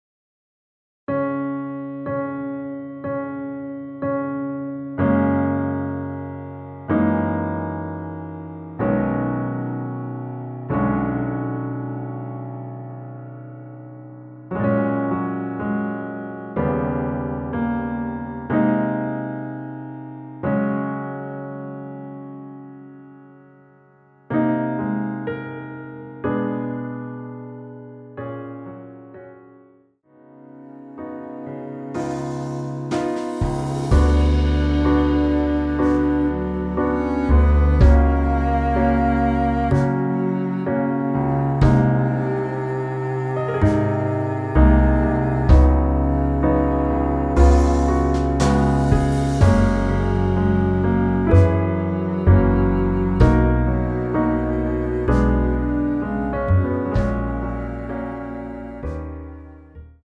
원키에서(-1)내린 MR입니다.
F#
앞부분30초, 뒷부분30초씩 편집해서 올려 드리고 있습니다.
중간에 음이 끈어지고 다시 나오는 이유는